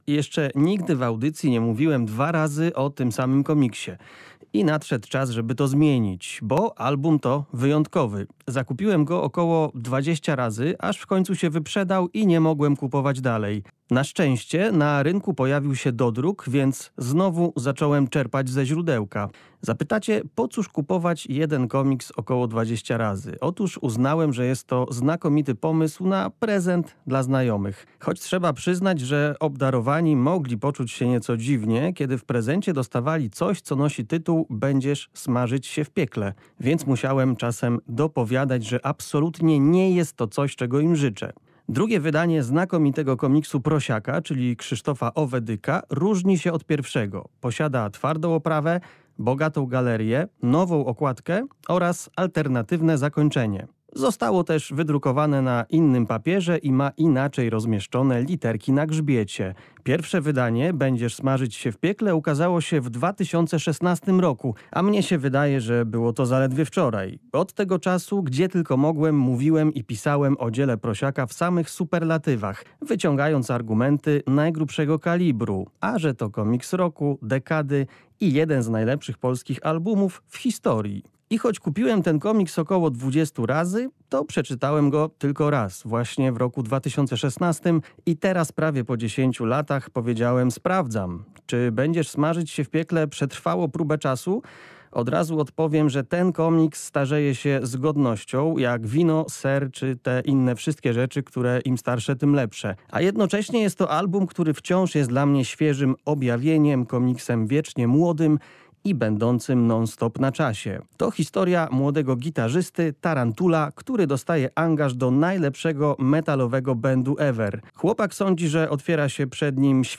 Jeszcze nigdy w audycji nie mówiłem dwa razy o tym samym komiksie.